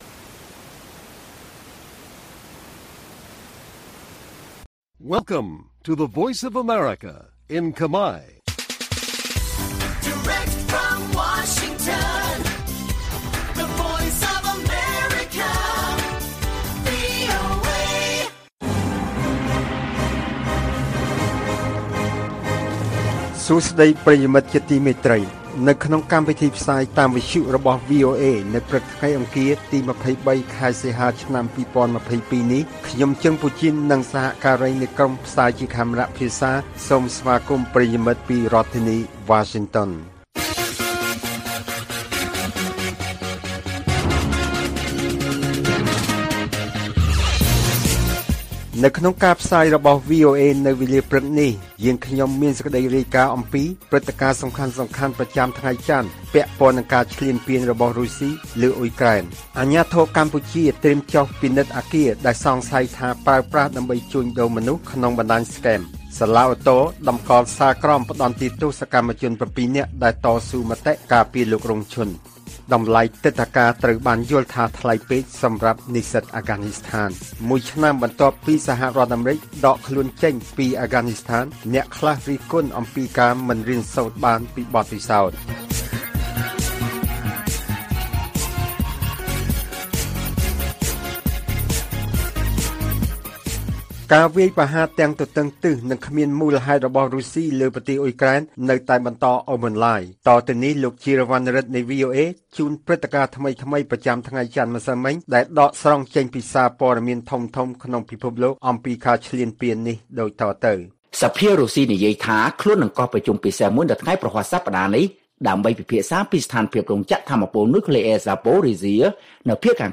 ព័ត៌មានពេលព្រឹក ២៣ សីហា៖ អាជ្ញាធរកម្ពុជាត្រៀមចុះពិនិត្យអគារដែលសង្ស័យថាប្រើប្រាស់ដើម្បីជួញដូរមនុស្សក្នុងបណ្តាញScam